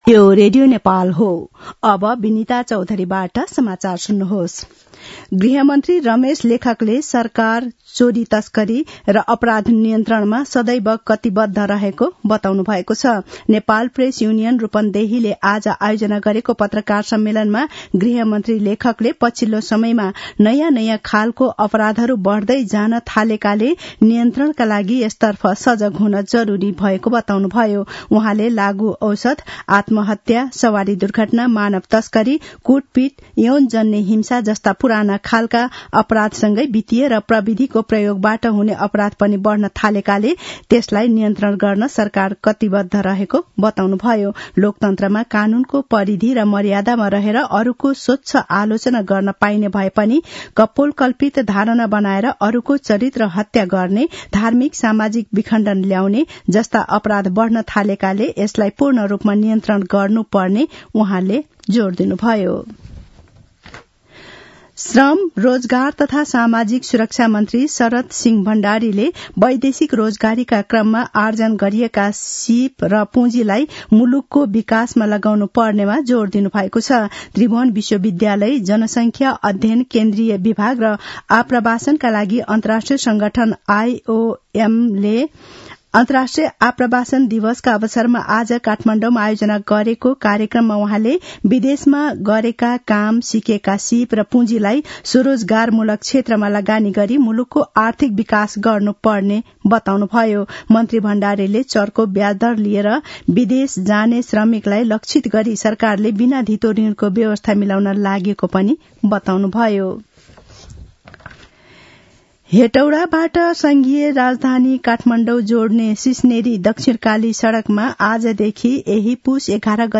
दिउँसो १ बजेको नेपाली समाचार : ४ पुष , २०८१